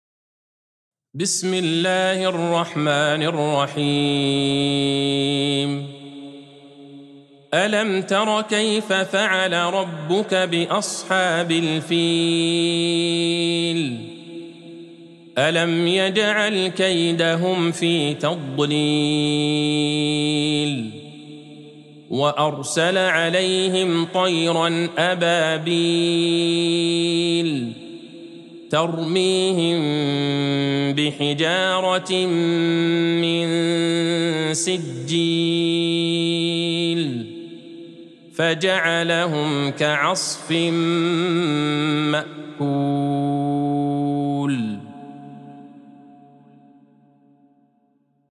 سورة الفيل Surat Al-Fil | مصحف المقارئ القرآنية > الختمة المرتلة ( مصحف المقارئ القرآنية) للشيخ عبدالله البعيجان > المصحف - تلاوات الحرمين